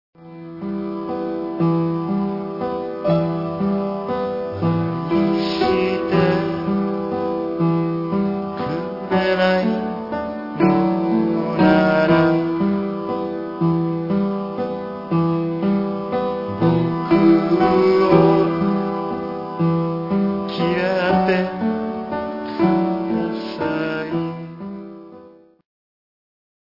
果てしなく重く、優しく、せつない歌とメロディの結晶をつむいだ珠玉の作品集！